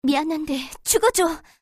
slayer_f_voc_skill_flameopera.mp3